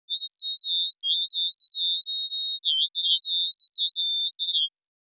You’ll find that it is pretty high pitched:
This high frequency intrigues me and I decide to check if Morse code is involved.